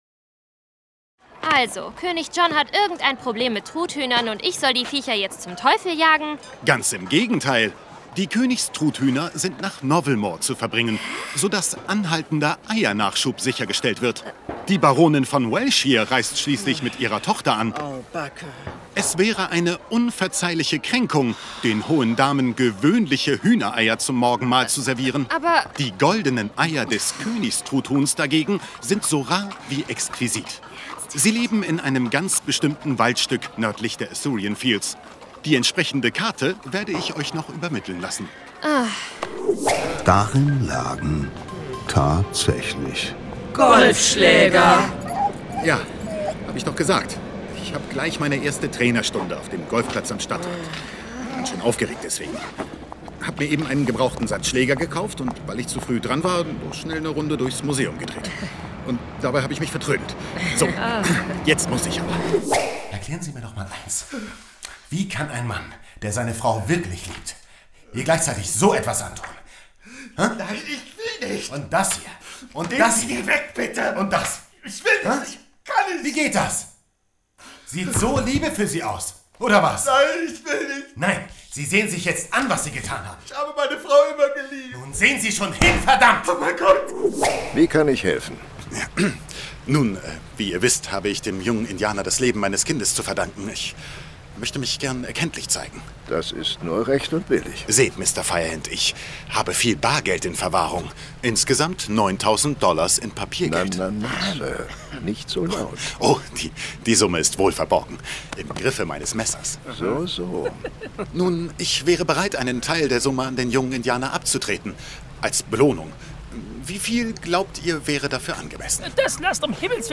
Hörspiele: